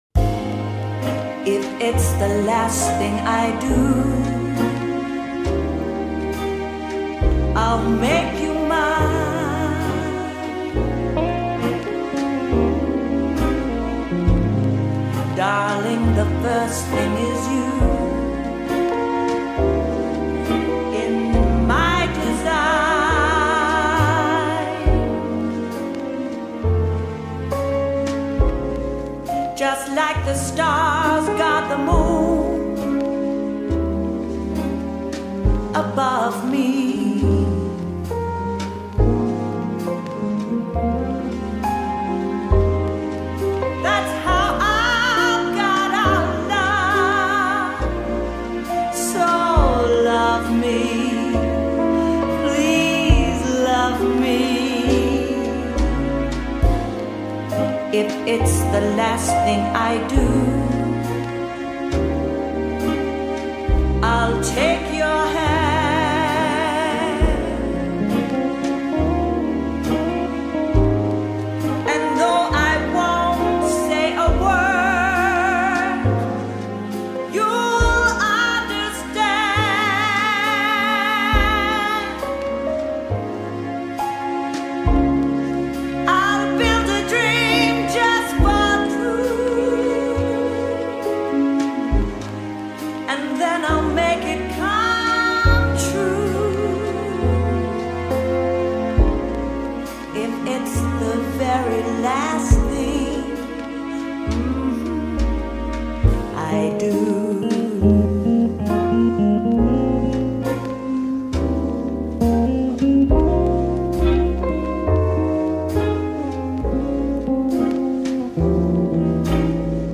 Female
English (Australian)
Adult (30-50), Older Sound (50+)
I have a warm, friendly, professional and versatile voice, with the ability to adapt to many characters and scripts.
VOICE ACTOR DEMOS